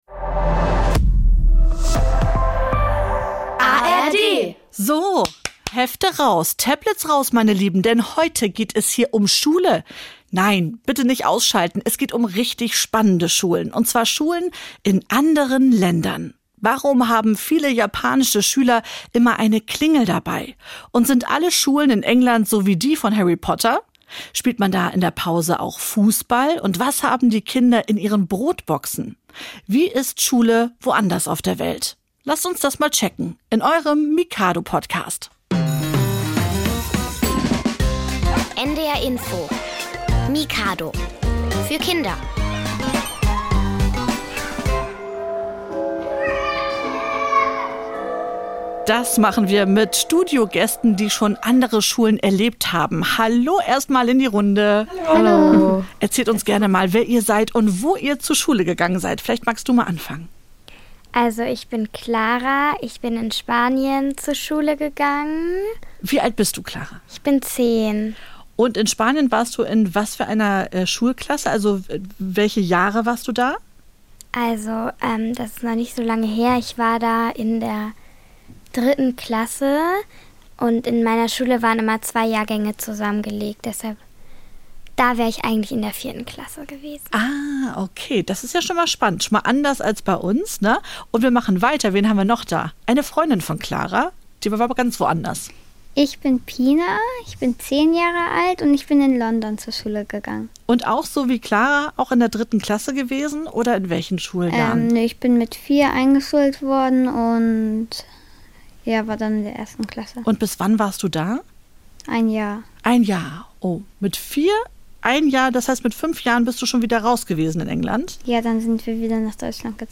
In mehreren Raterunden treten Kinder gegeneinander an.